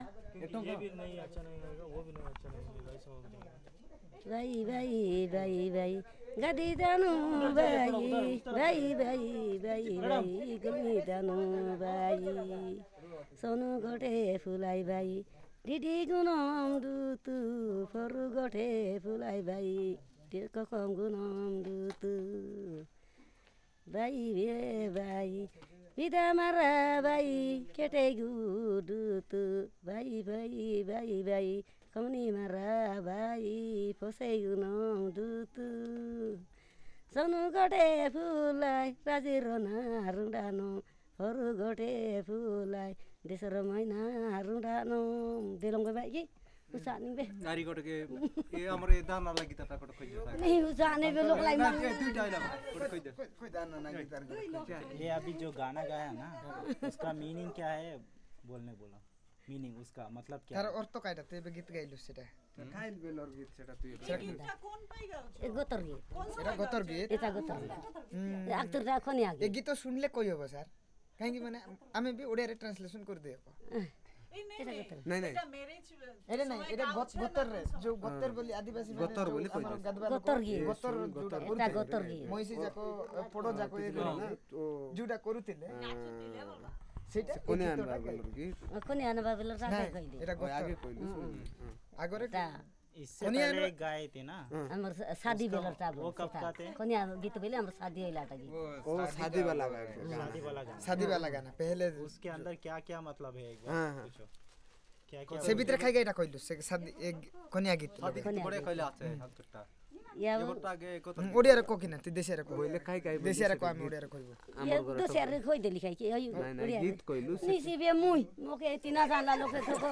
Perfomance of Goter song